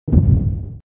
detonation_ball01.wav